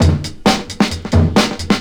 • 106 Bpm Drum Loop F Key.wav
Free drum loop - kick tuned to the F note. Loudest frequency: 1356Hz
106-bpm-drum-loop-f-key-DfH.wav